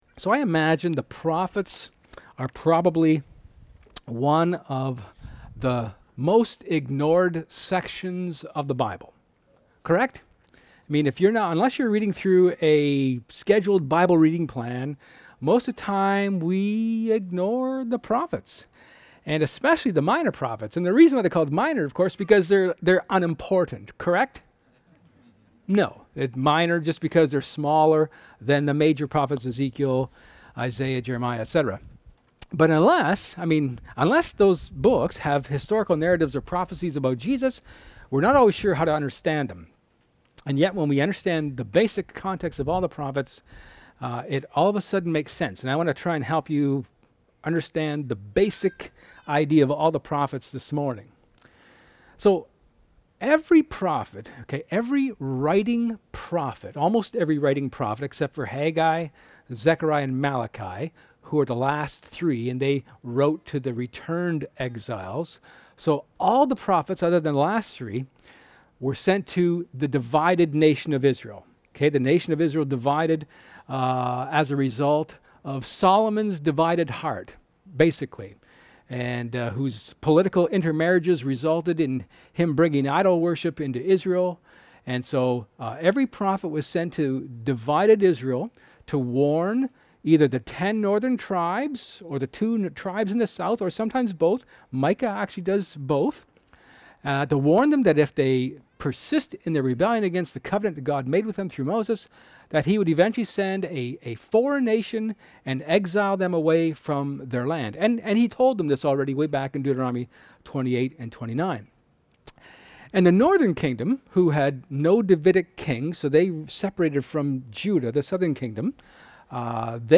Zephaniah 1:1-2:3 Service Type: Sermon